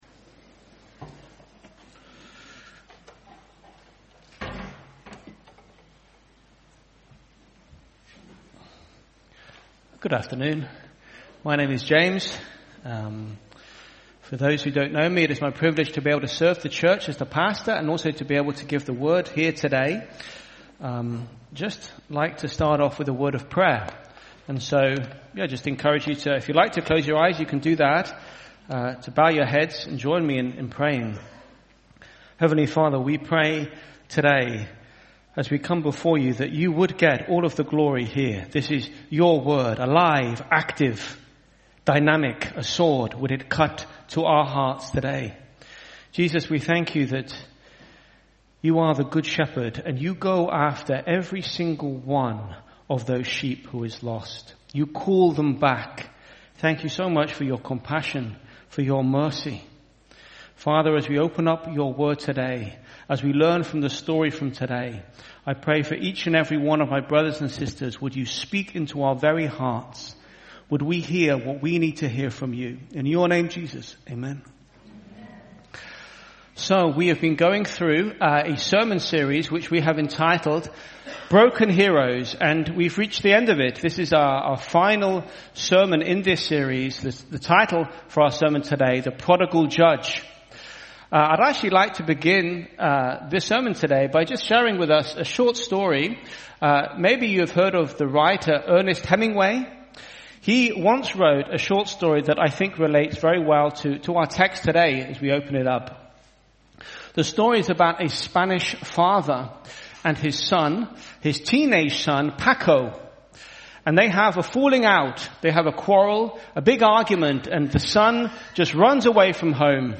IBC Hamburg Sermon